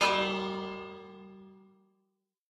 bell_use02.ogg